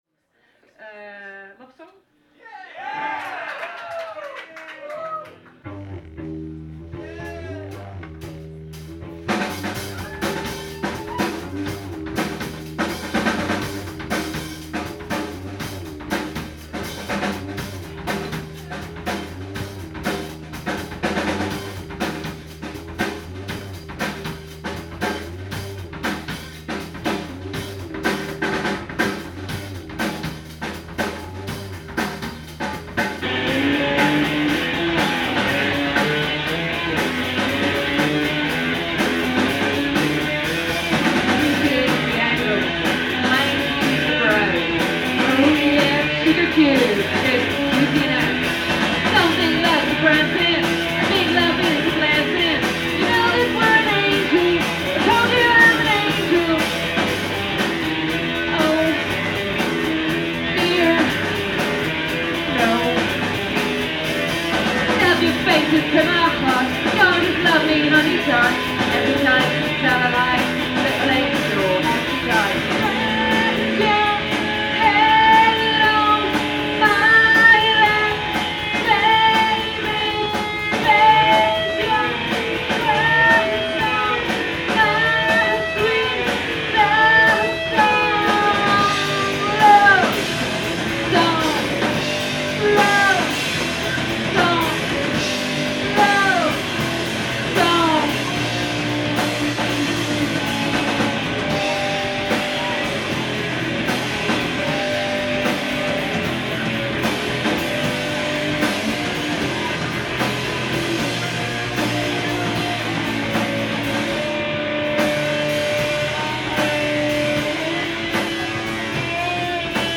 steadily intense drumming
alternatingly subdued and fierce voice
repetitive, rolling bass lines
frenetic guitar noise
Live at PA’s Lounge